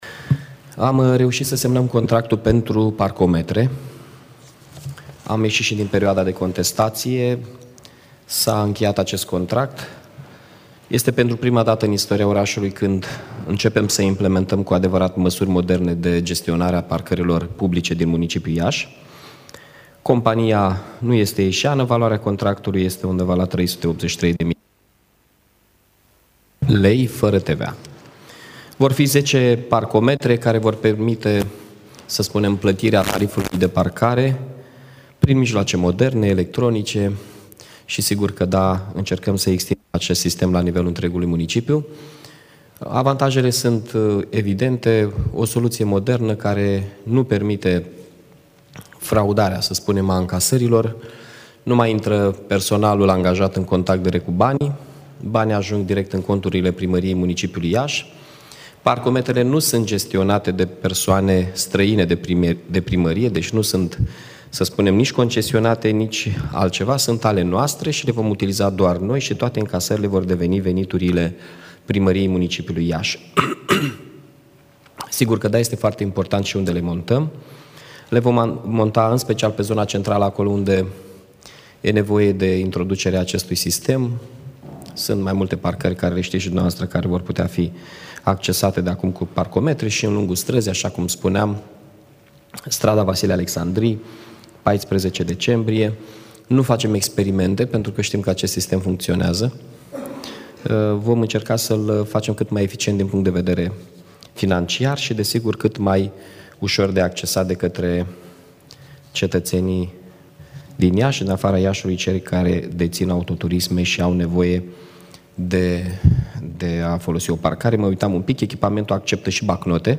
Primarul municipiului Iași, Mihai Chirica